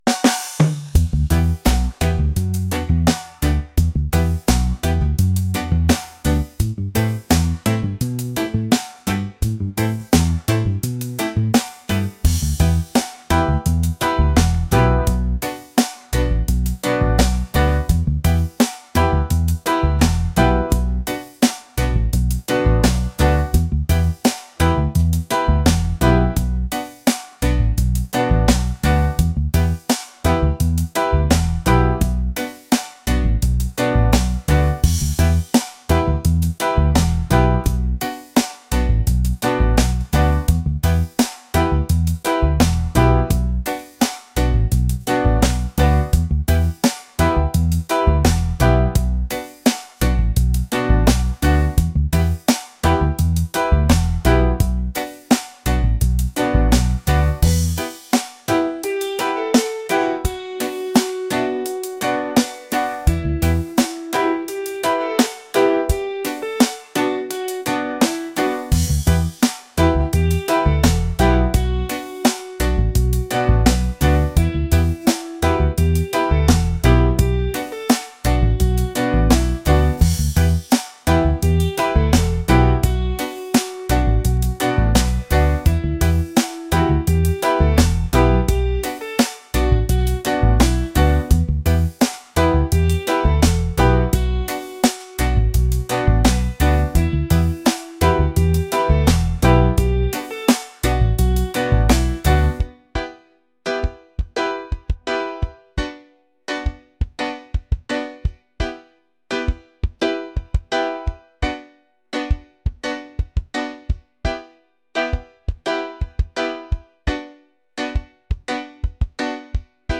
romantic | laid-back | reggae